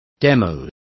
Complete with pronunciation of the translation of demos.